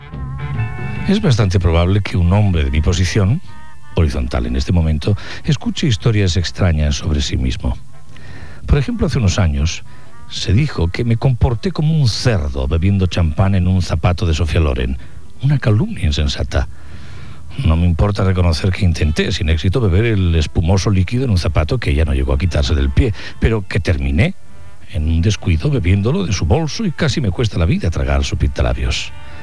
Inici de la lectura de "Mi perra" de Groucho Marx.
Programa realitzat des de Cibeles FM, Madrid, i emès també per Onda Rambla Catalunya.